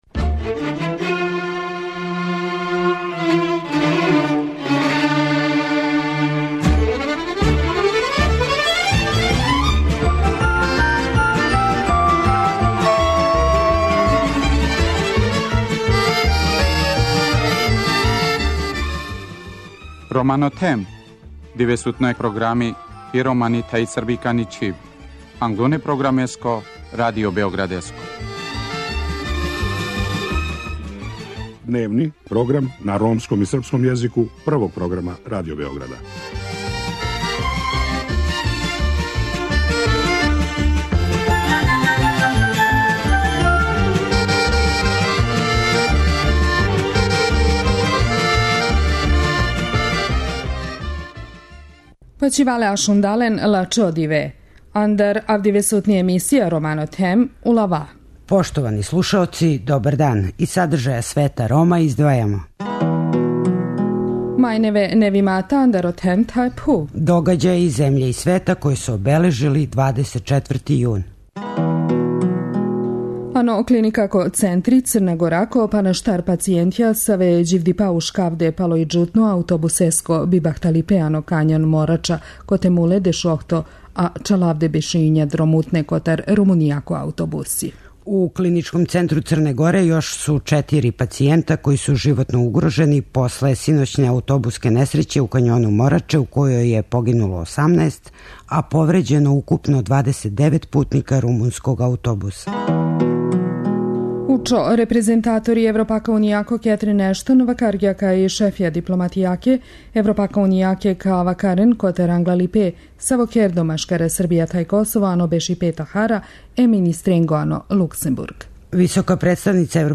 Гост емисије је Јелко Кацин, известилац Европског Парламента за западни Балкан који говори о резултатима Србије на пољу интеграције Рома.